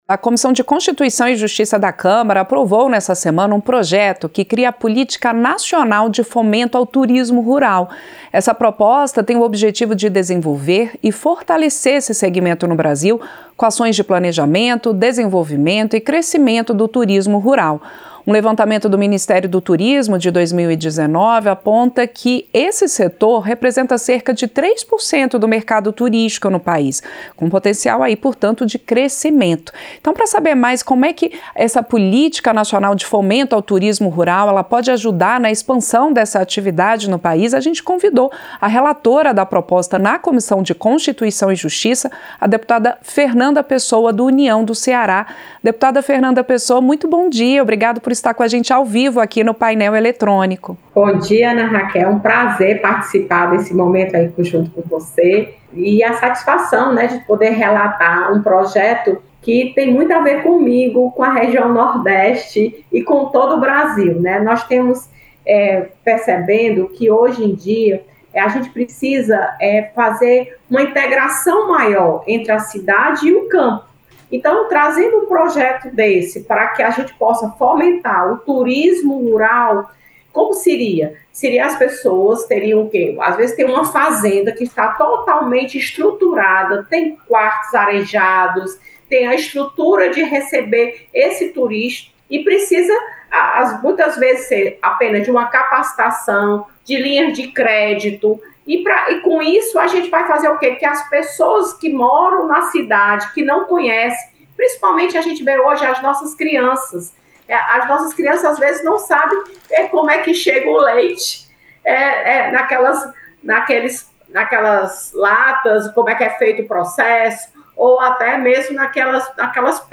• Entrevista - Dep. Fernanda Pessoa (União-CE)
Programa ao vivo com reportagens, entrevistas sobre temas relacionados à Câmara dos Deputados, e o que vai ser destaque durante a semana.